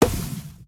Minecraft Version Minecraft Version 1.21.5 Latest Release | Latest Snapshot 1.21.5 / assets / minecraft / sounds / entity / shulker_bullet / hit4.ogg Compare With Compare With Latest Release | Latest Snapshot